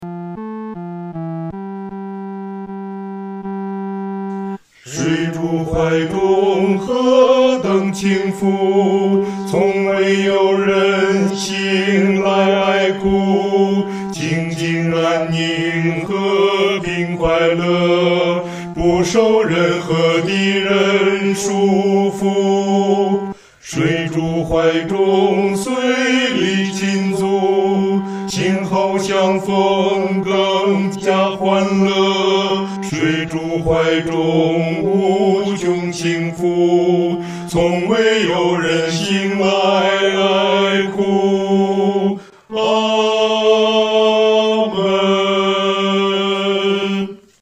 男高
其旋律、和声构成无比宁静的气氛，在丧礼中给人莫大的安慰。